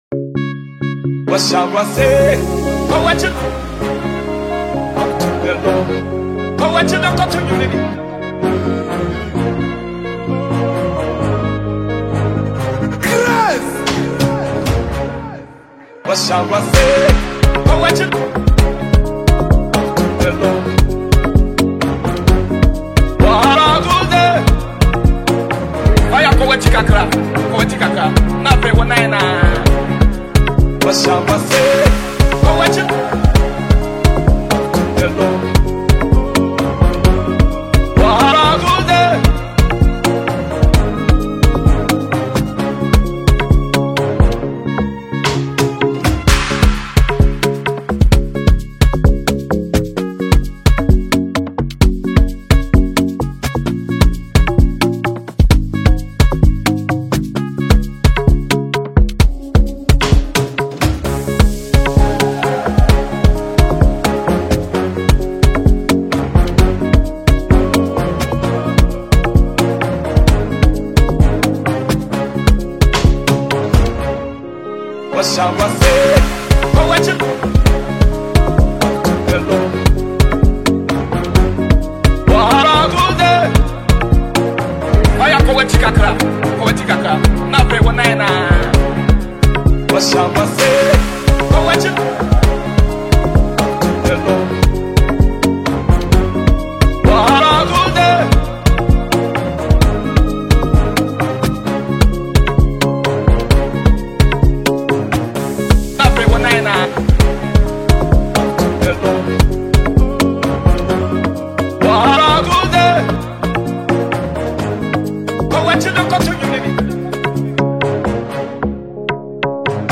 gospel line
danceable Tik Tok type music